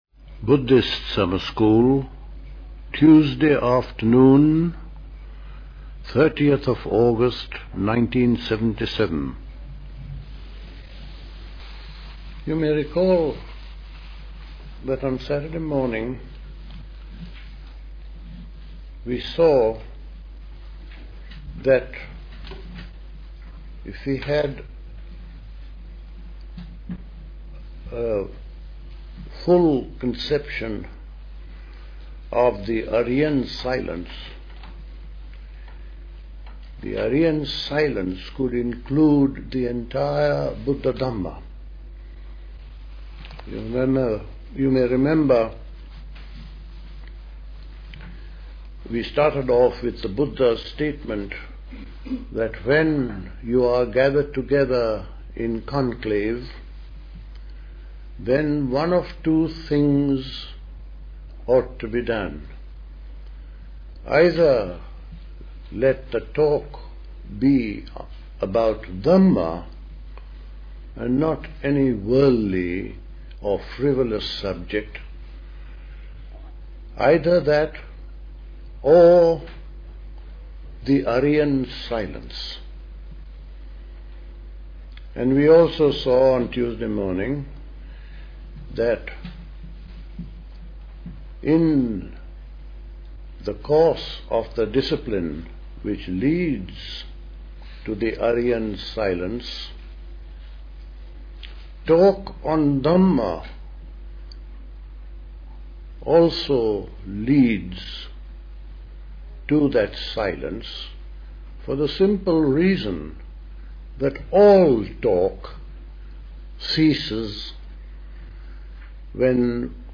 The Buddhist Society Summer School